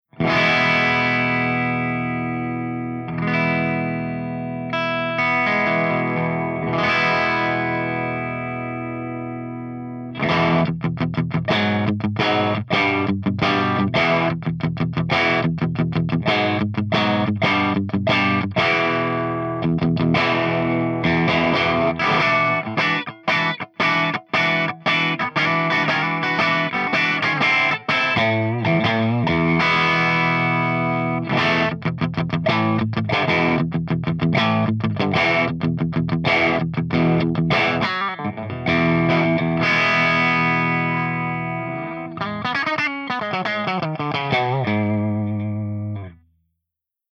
155_EVH5150_CH1CLEAN_V30_P90